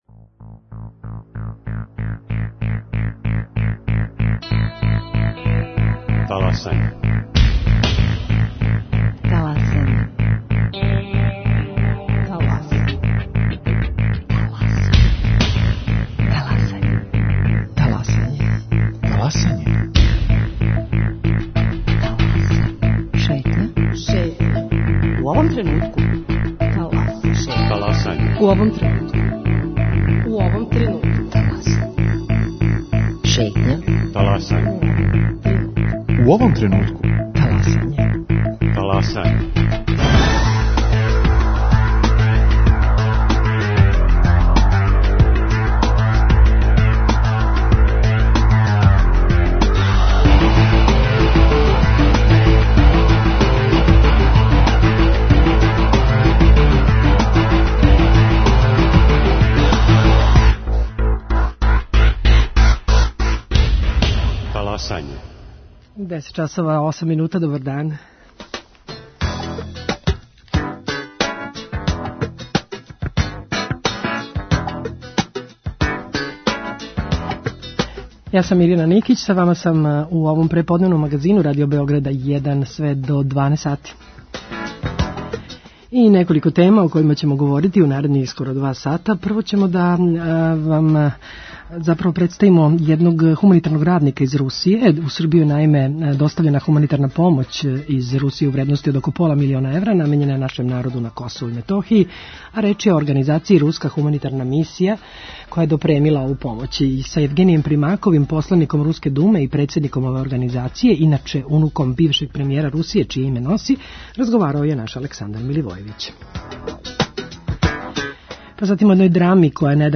Психолошке приче средом "Корак напред": Како разговарамо, колико се разумемо и да ли слушамо једни друге?